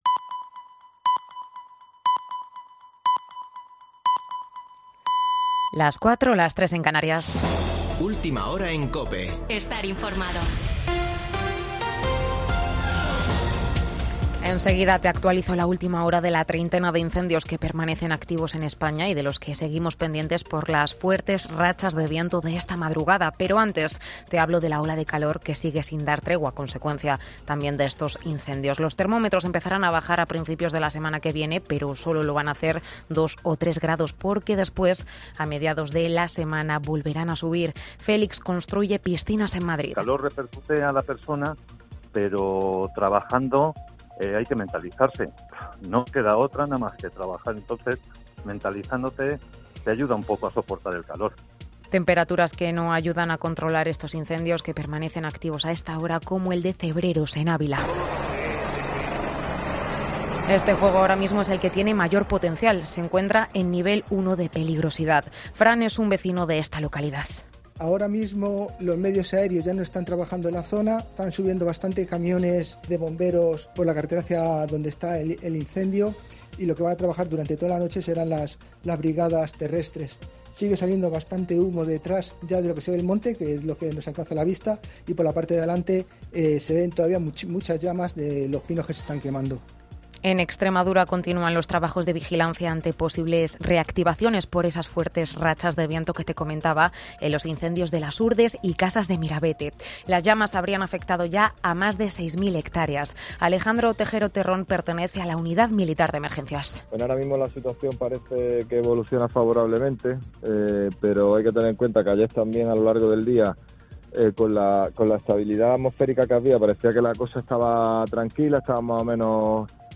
Boletín de noticias de COPE del 17 de julio de 2022 a las 04:00 horas